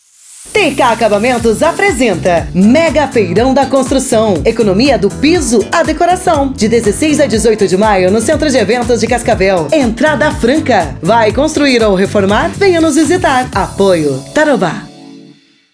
ROTEIRO DE ÁUDIO - DESIGNER PERFIGLASS - MEGA FEIRÃO -  Duração: 15 SEGUNDOS
LOCUÇÃO: (voz firme, convidativa e com ritmo cadenciado)